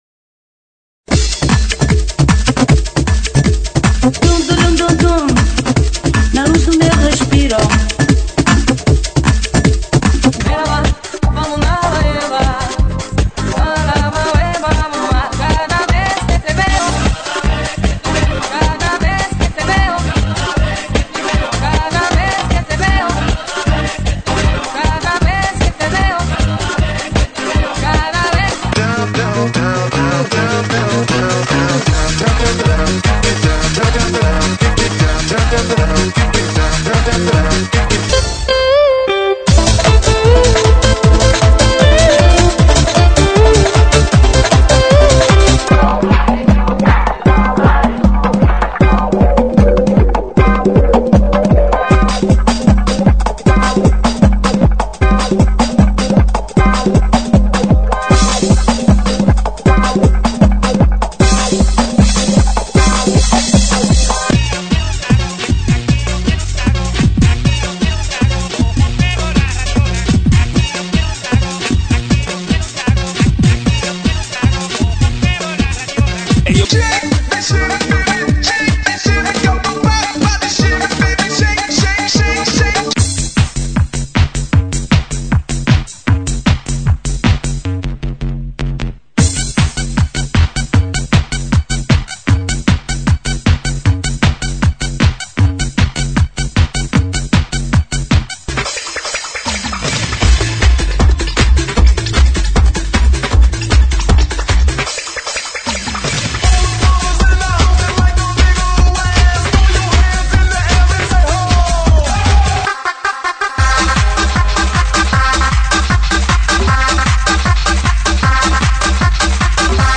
GENERO: DANCE – REMIX
LATINO,